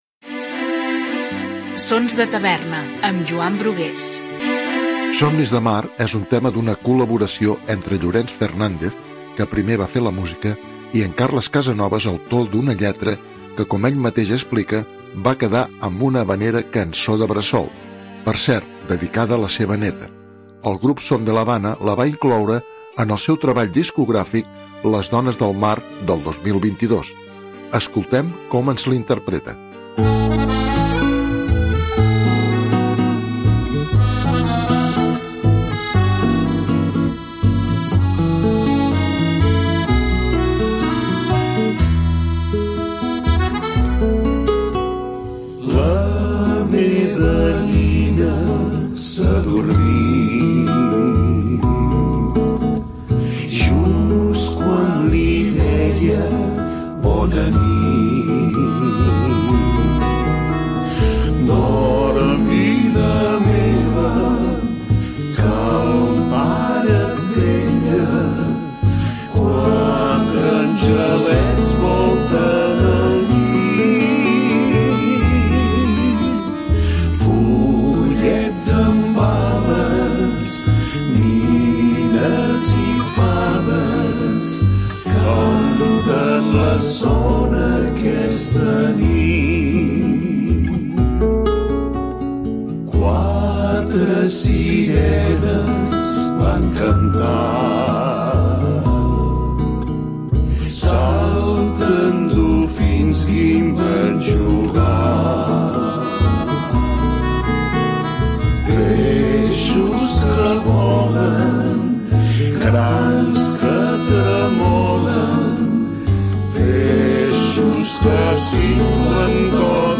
va quedar una havanera-cançó de bressol